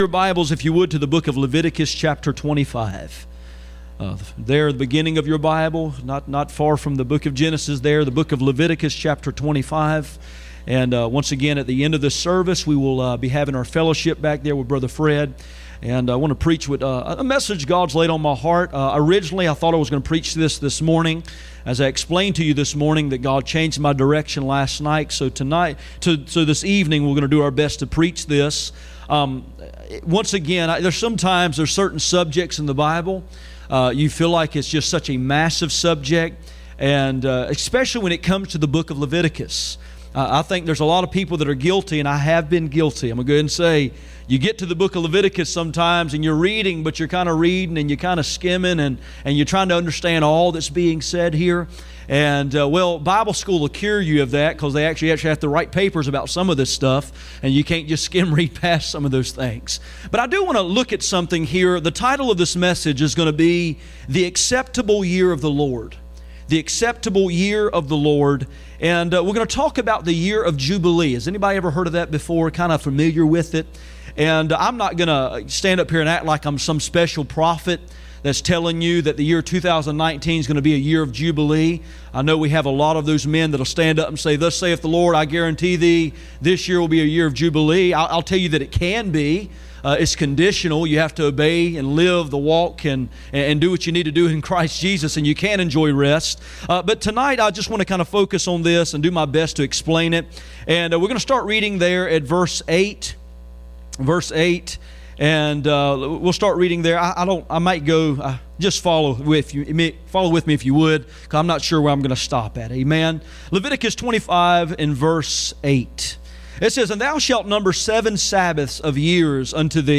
None Passage: Leviticus 25:8-13, Isaiah 61:1-3, Luke 4:17-19 Service Type: Sunday Evening